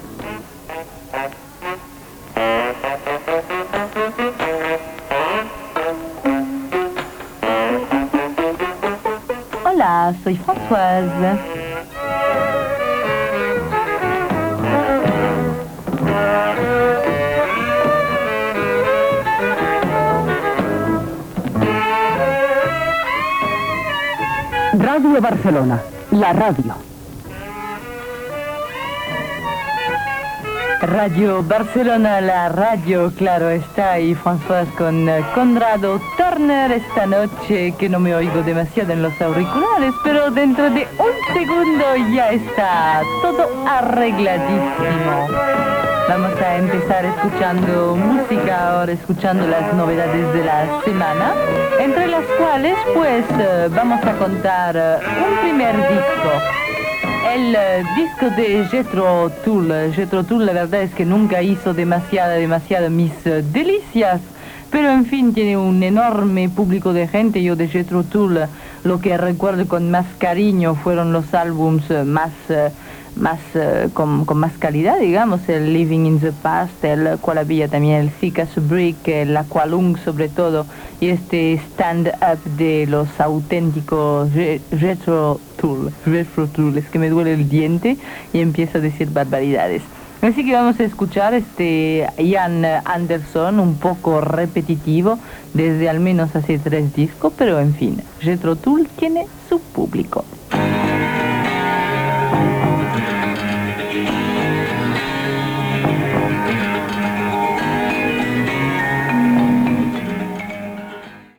Identificació de l'emissora, sintonia, presentació del programa i de la primera cançó
Musical